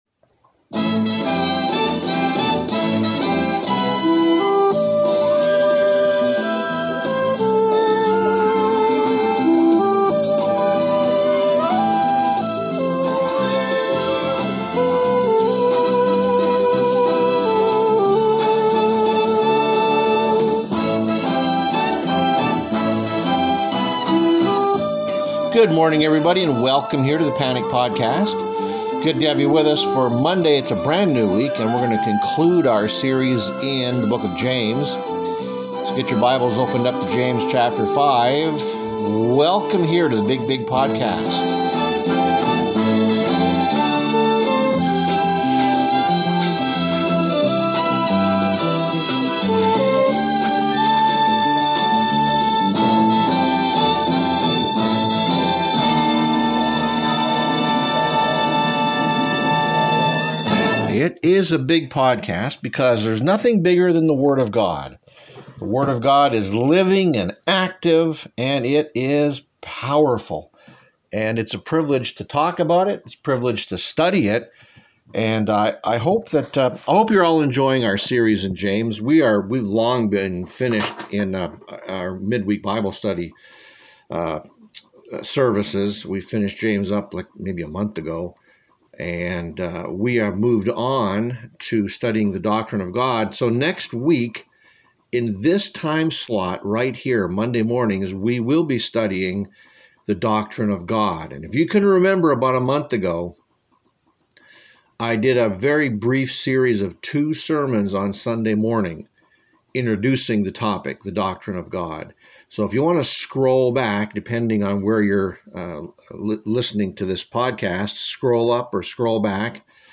The Sunday Sermon